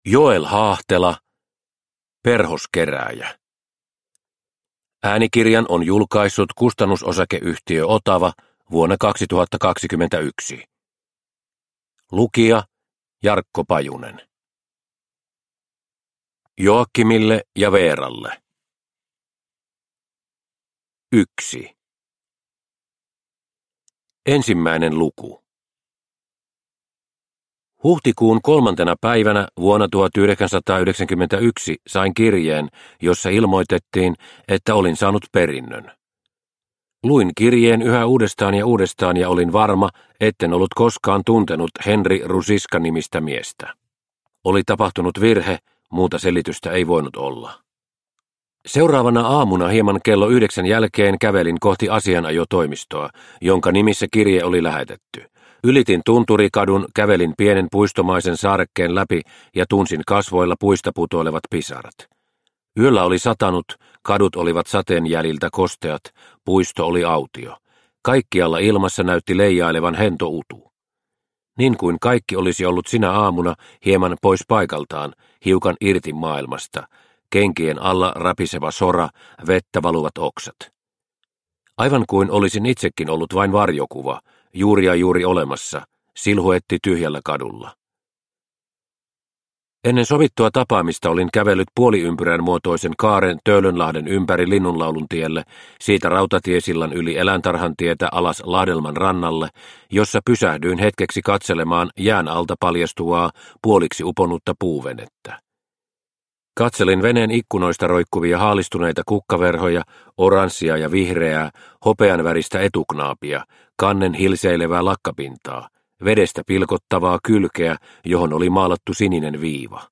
Perhoskerääjä – Ljudbok – Laddas ner